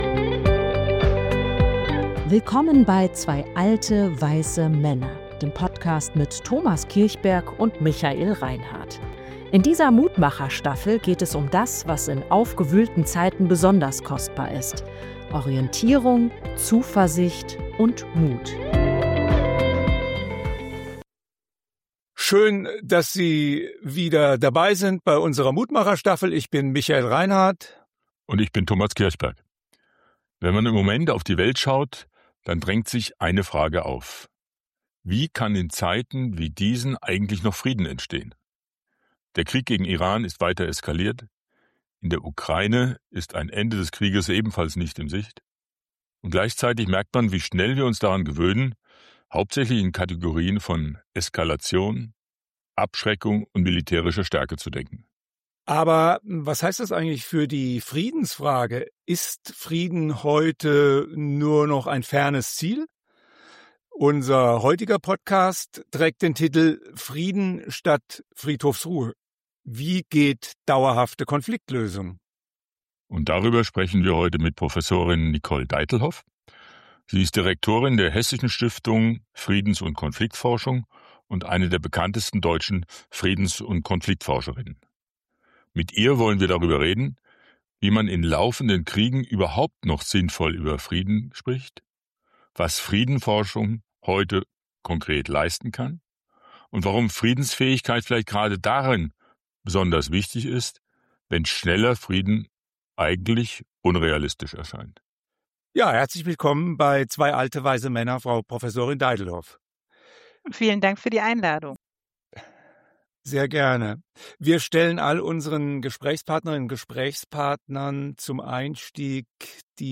Genau darüber sprechen wir in dieser Folge mit Prof. Dr. Nicole Deitelhoff, Direktorin der Hessischen Stiftung Friedens- und Konfliktforschung und eine der bekanntesten deutschen Friedens- und Konfliktforscherinnen.